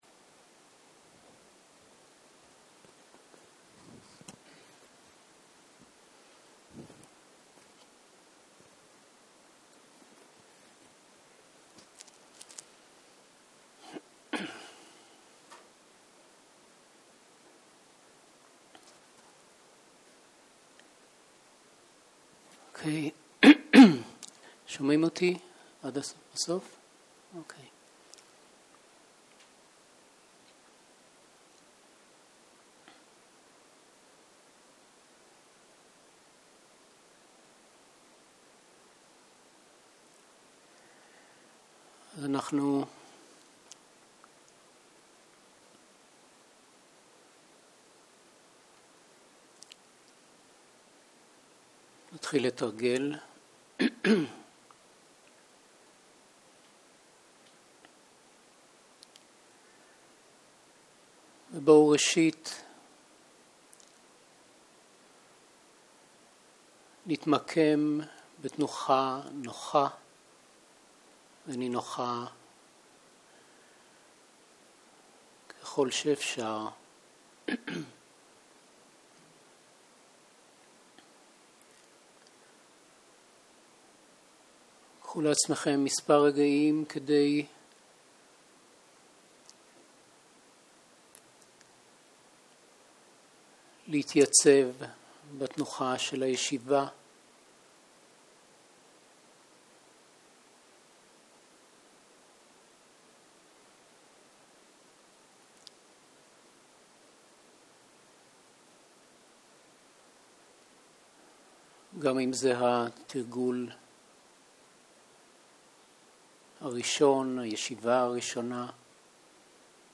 ערב - מדיטציה מונחית